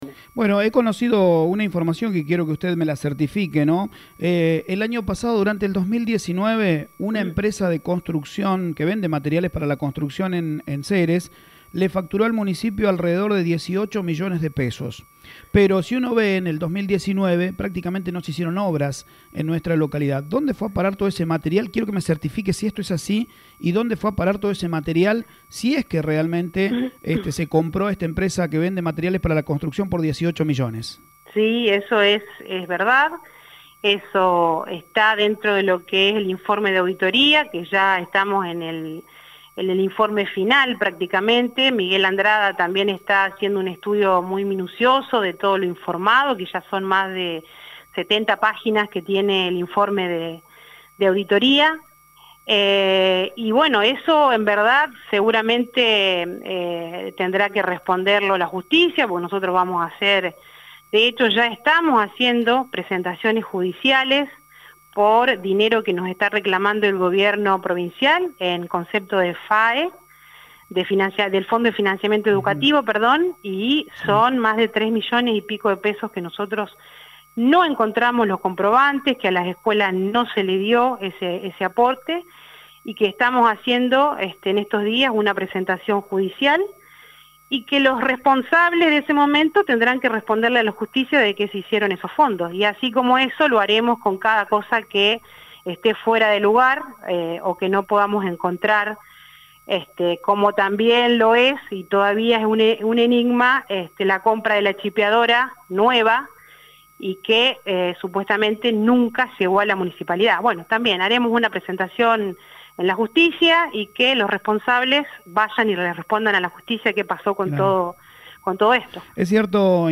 “Los responsables de eso, deberán responder ante la justicia” aseguró la Intendente Alejandra Dupouy a Radio Eme Ceres.